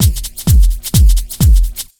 129BEAT1 4-R.wav